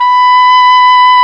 Index of /90_sSampleCDs/Roland L-CDX-03 Disk 1/CMB_Wind Sects 1/CMB_Wind Sect 1
WND OBOE3 B5.wav